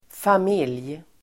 Uttal: [fam'il:j]